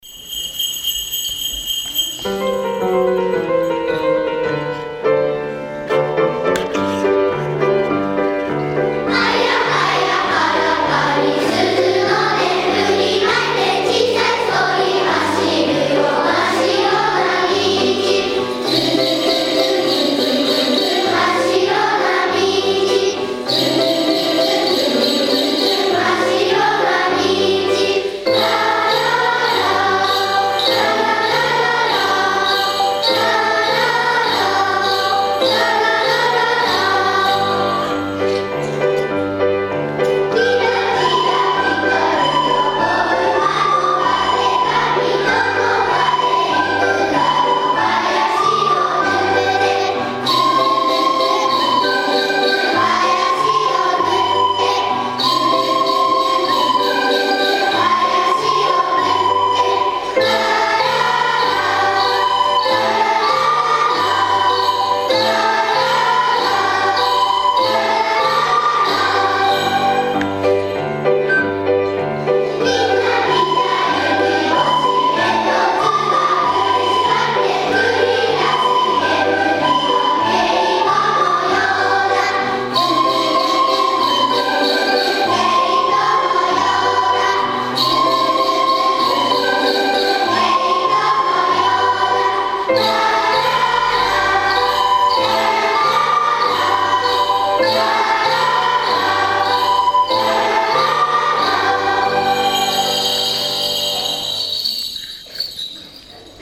令和元年１２月１０日（火）　音楽朝会がありました。
今日は音楽朝会でした。１２月の歌は「冬のうた」少し寒い体育館に子供たちの元気なきれいな歌声が響き渡りました。
画像をクリックすると歌声を聞くことができます。